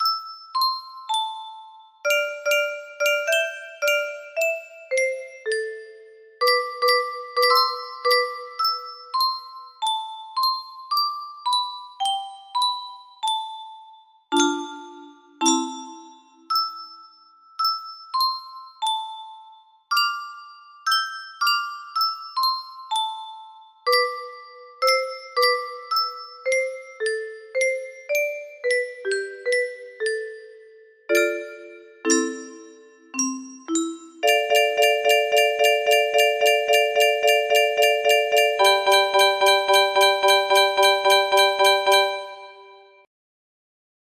Main melody adapted for Muro Box 20